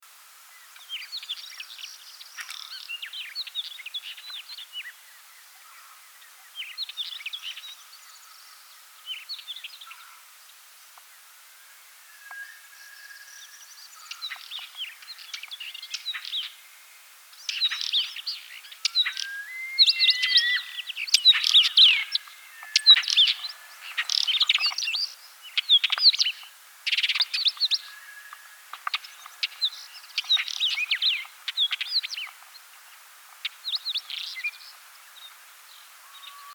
نام فارسی : چکچک ابلق
نام انگلیسی :Pied Wheatear
نام علمی :Oenanthe pleschanka